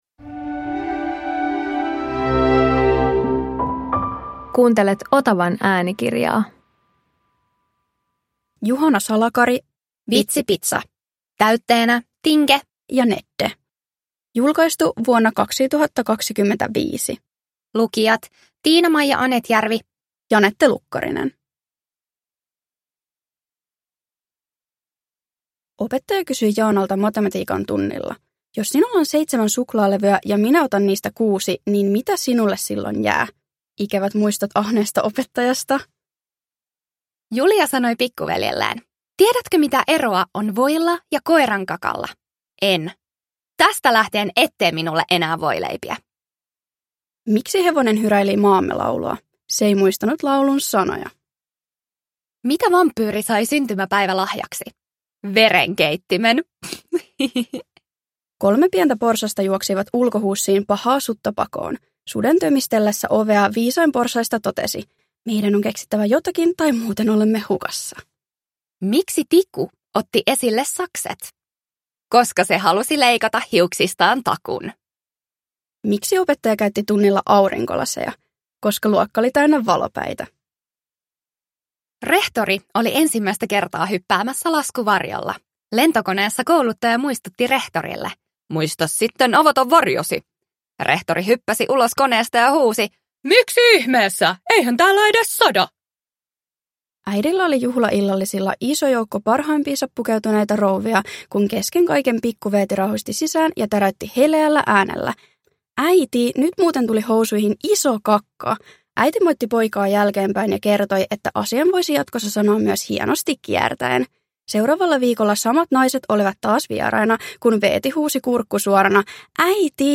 Vitsipitsa – täytteenä Tinke ja Nedde – Ljudbok